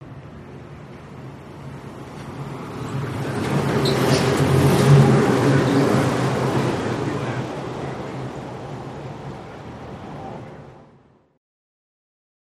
Monorail, Disneyland, Pass By, Some Squeaks